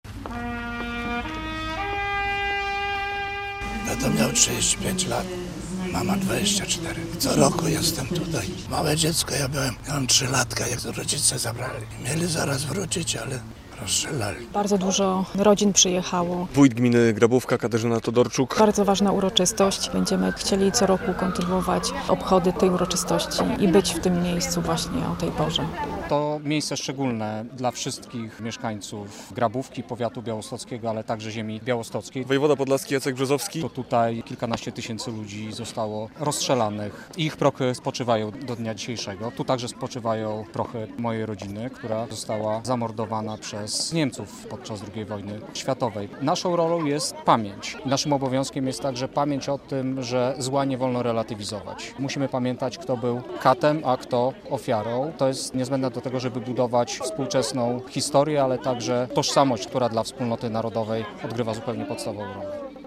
Uroczystości w Grabówce - relacja